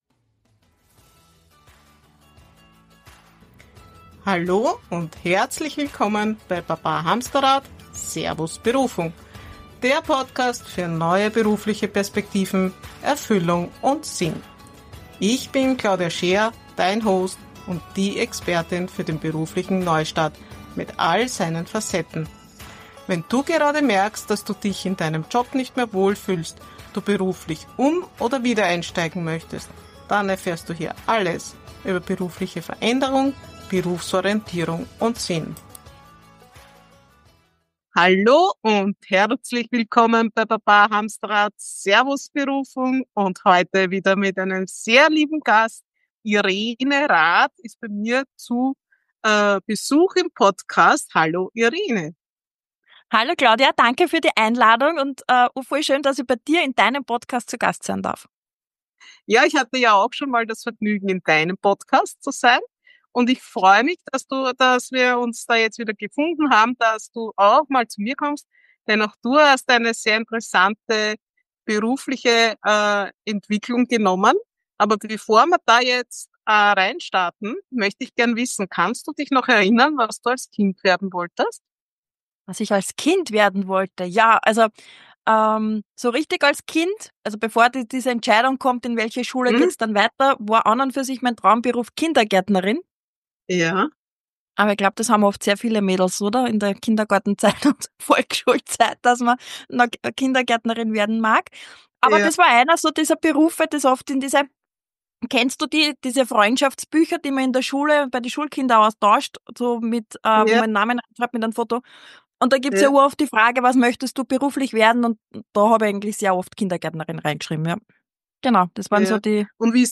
Interview ~ Baba Hamsterrad, Servus Berufung Podcast